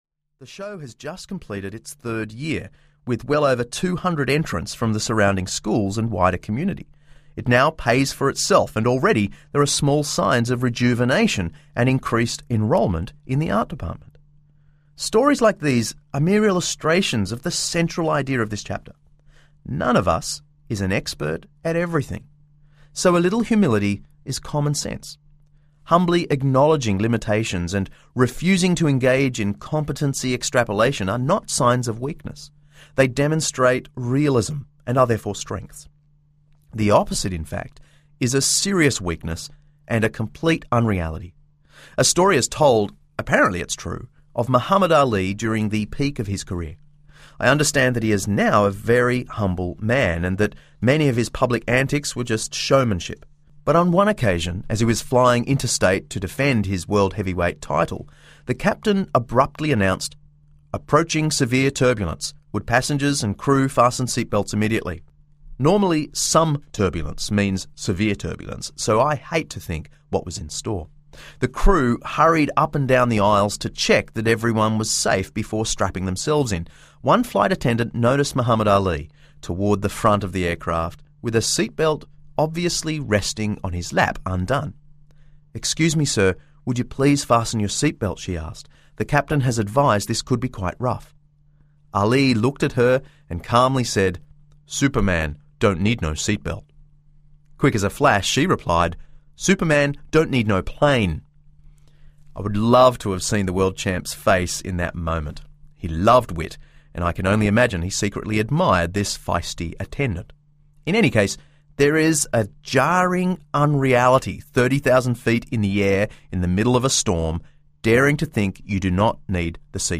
Humilitas Audiobook